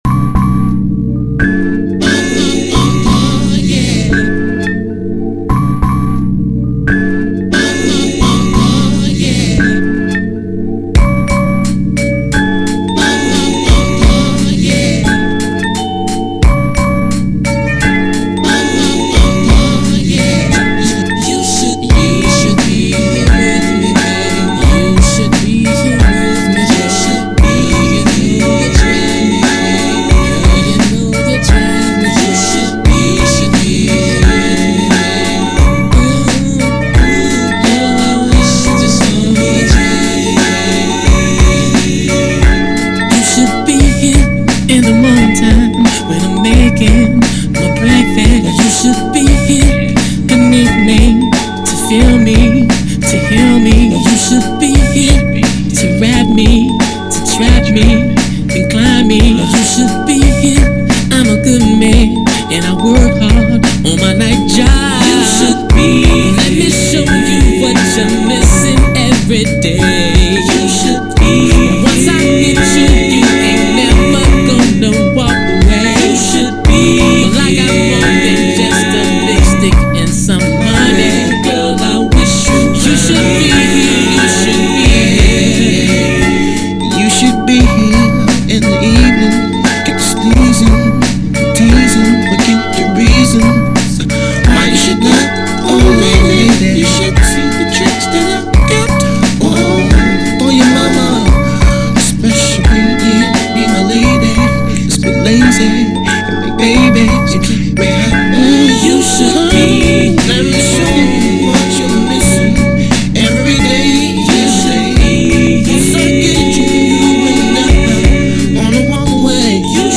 Another round of remixes